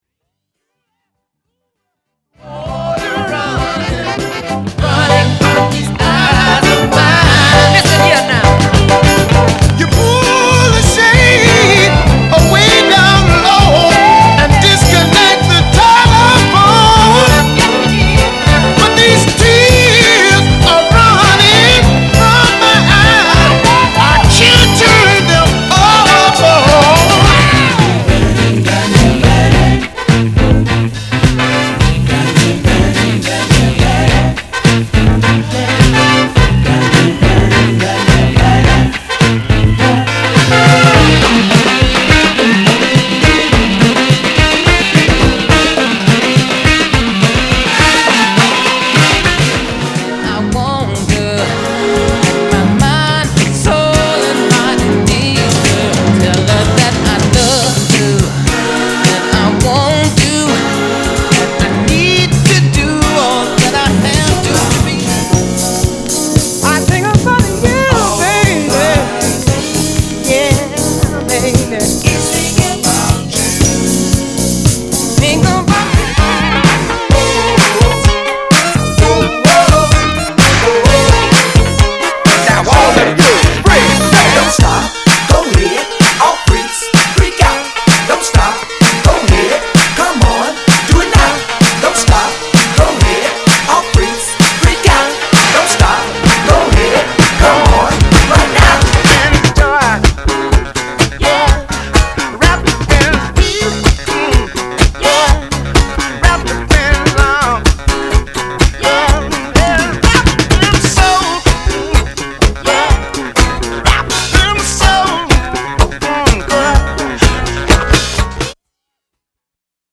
/盤質/両面やや傷あり再生良好です/US PRESS